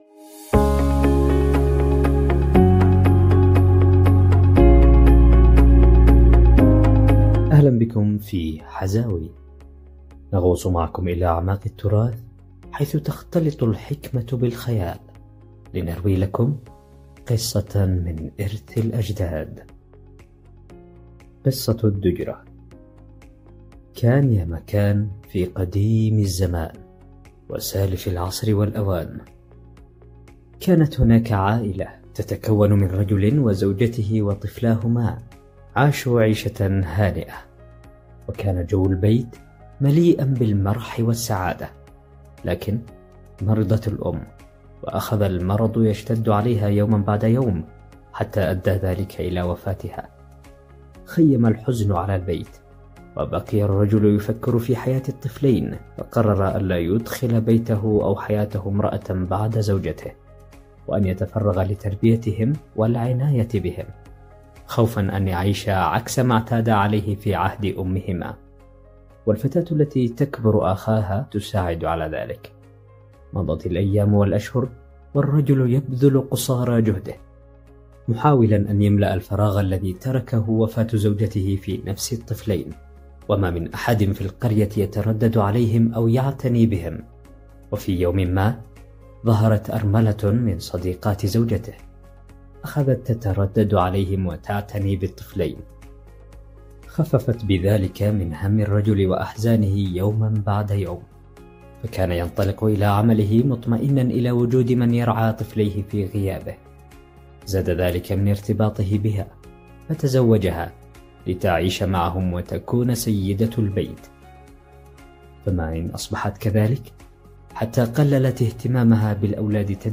إعداد وتقديم